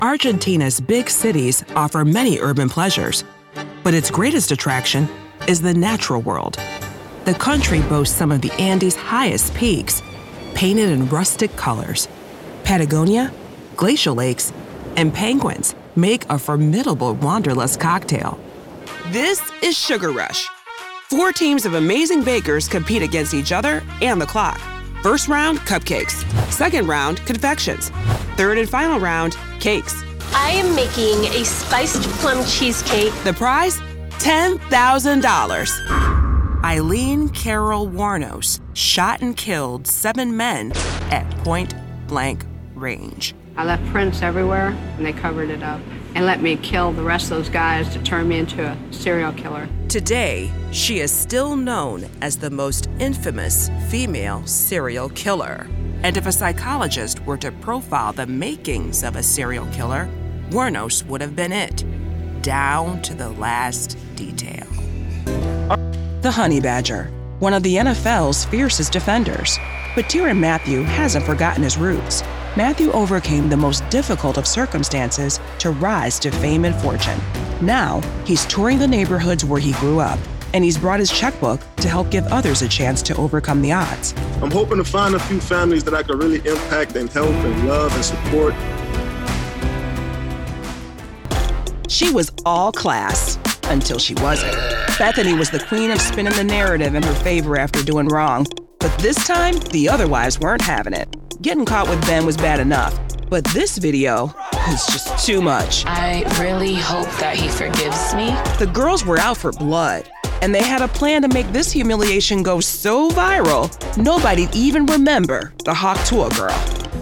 American female
voice actor
TV In-Show Narration
I use Source Connect Standard in my pro home studio (not shown here).